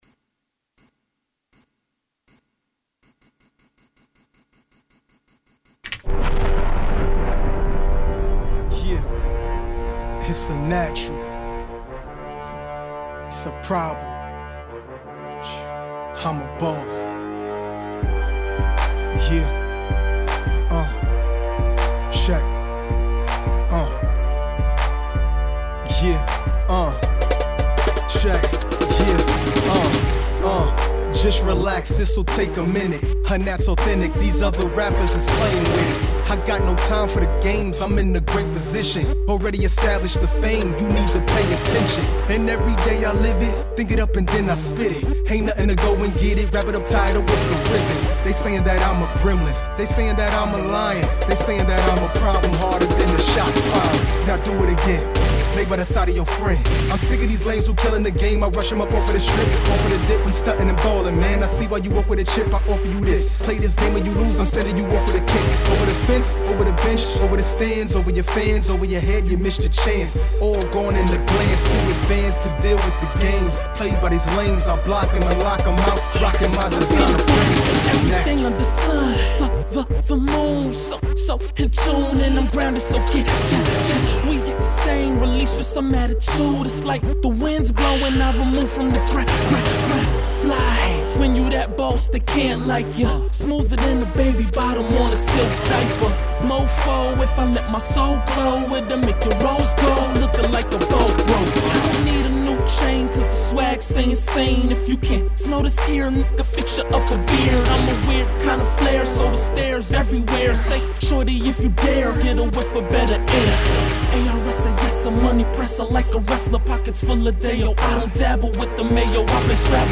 " his distinct baritone grabs you